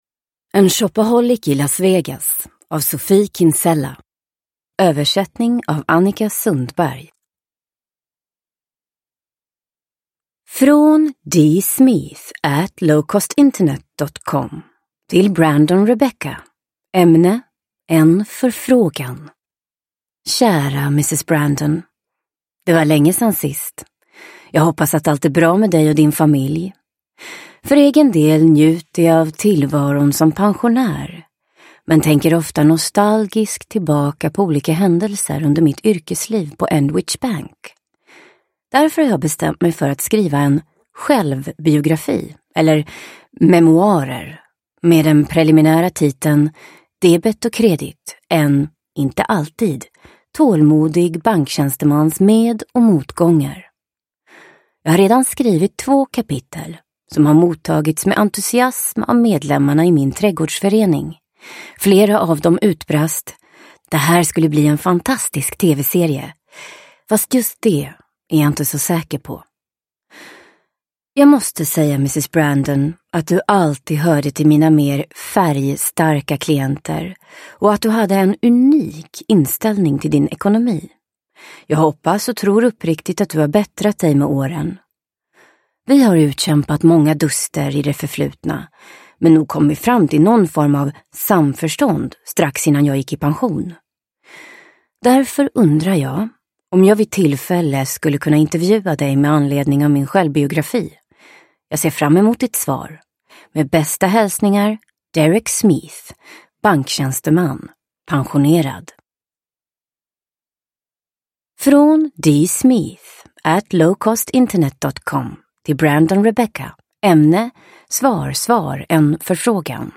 En shopaholic i Las Vegas – Ljudbok – Laddas ner